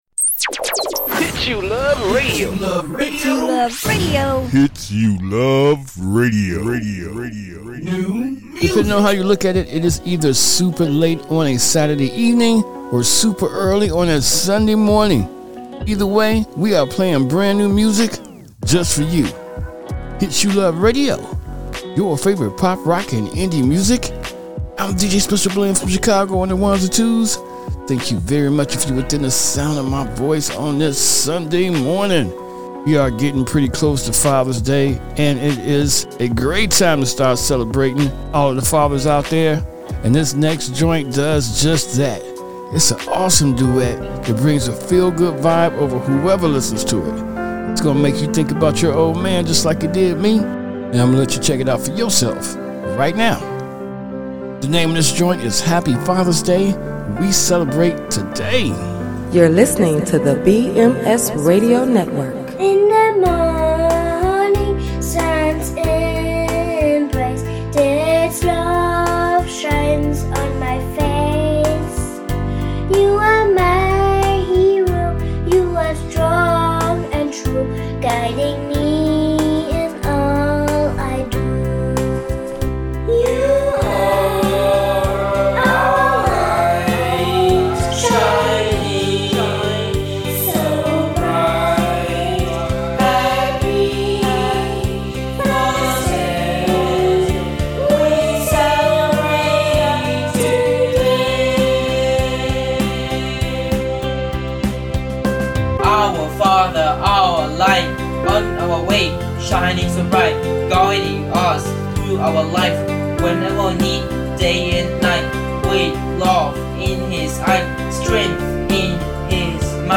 Radio Live Song